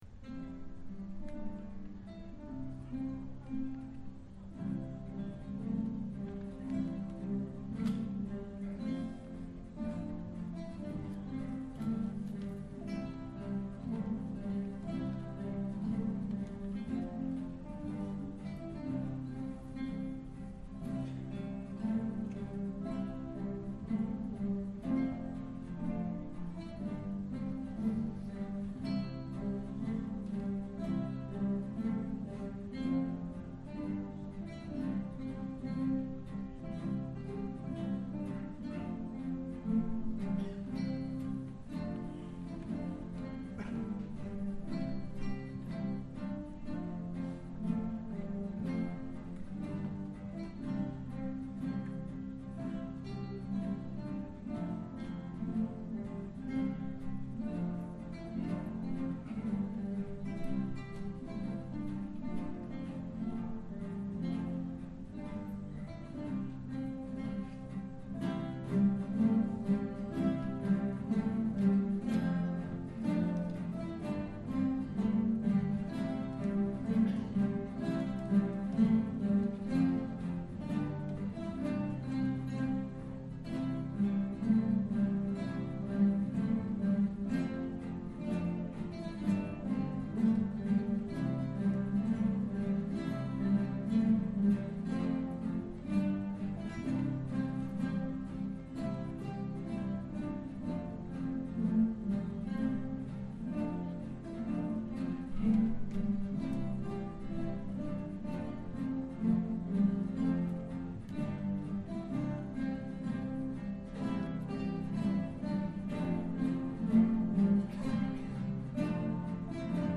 Egan's Polka - Junior Guitar Ensemble